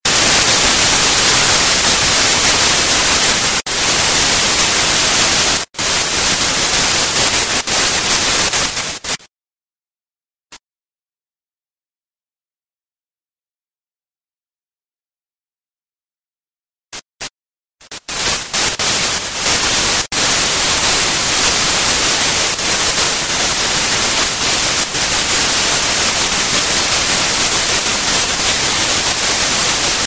Sounds of Saturns moon Rhea
Category: Animals/Nature   Right: Personal